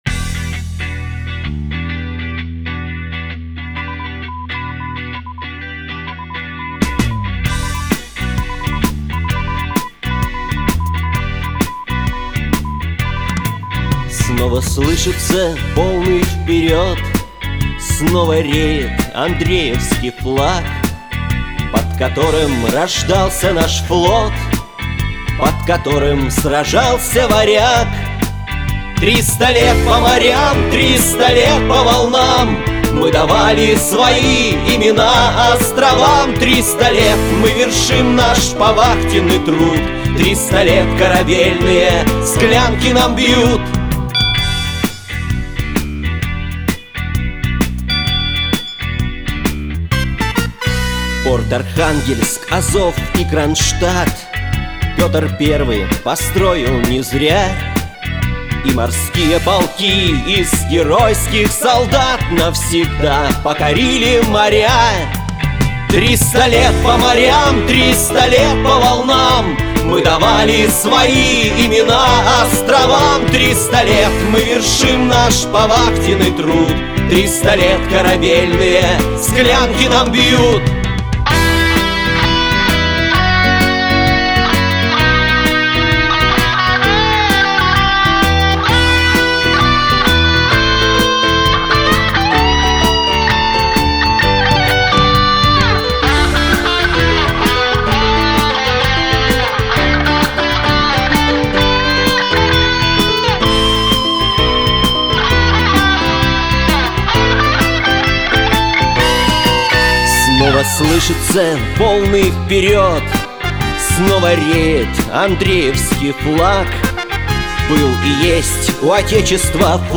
Главная / Computer & mobile / Мелодии / Патриотические песни